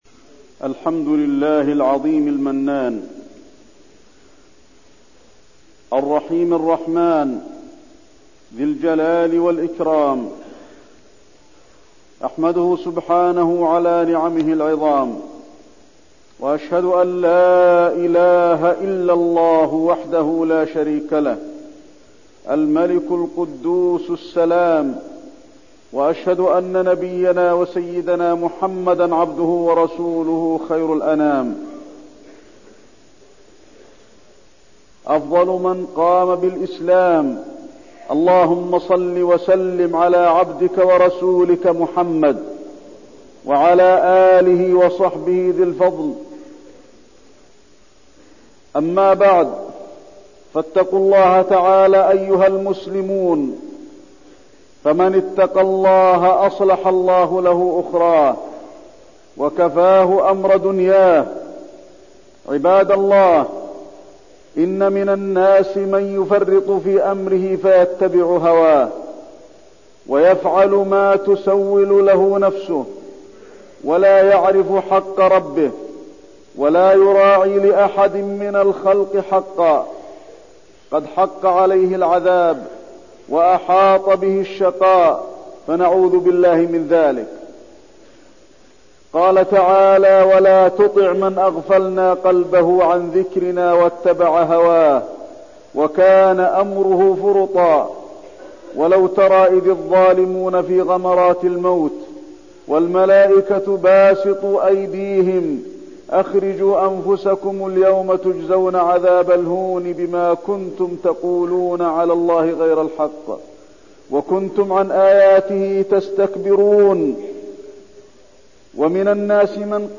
تاريخ النشر ٥ شعبان ١٤١٠ هـ المكان: المسجد النبوي الشيخ: فضيلة الشيخ د. علي بن عبدالرحمن الحذيفي فضيلة الشيخ د. علي بن عبدالرحمن الحذيفي اغتنام طرق الخير